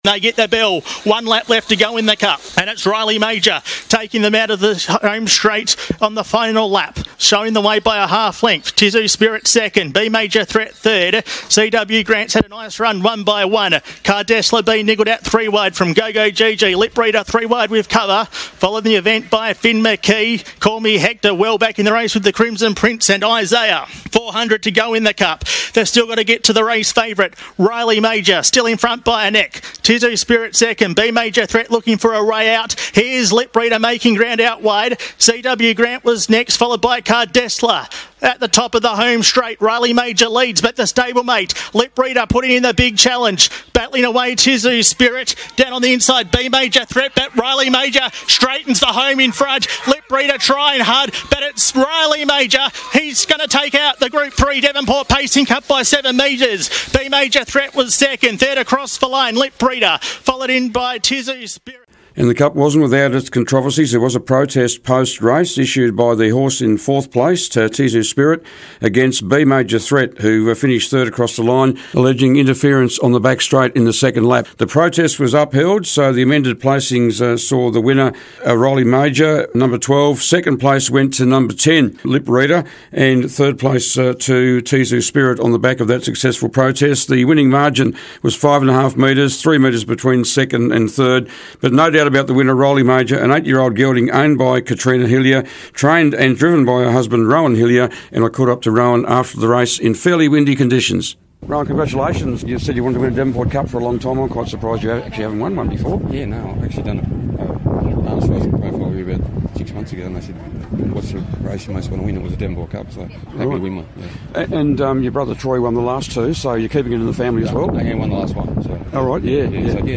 Listen to the closing stages of the cup